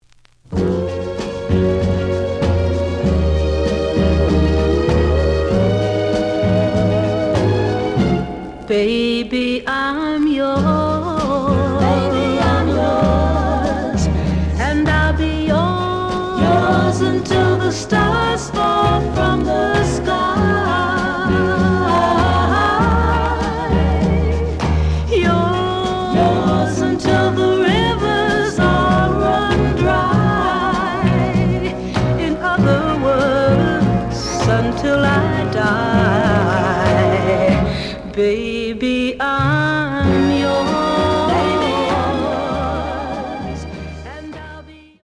The audio sample is recorded from the actual item.
●Genre: Soul, 60's Soul
Slight sound cracking on A side.